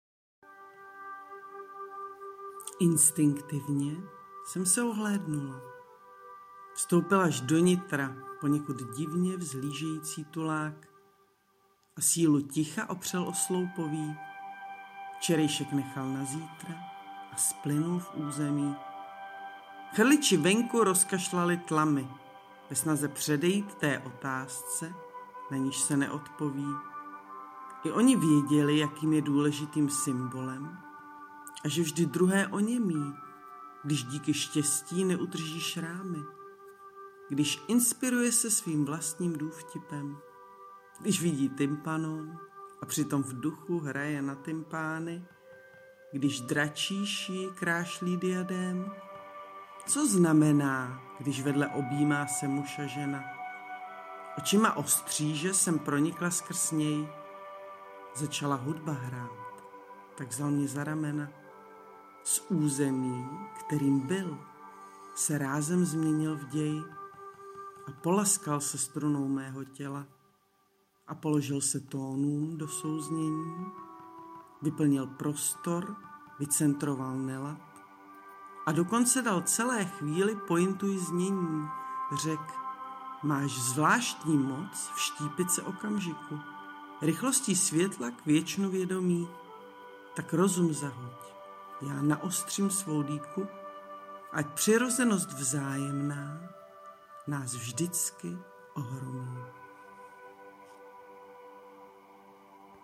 Ta recitace s hudbou z toho dělá až mystické spojení. :)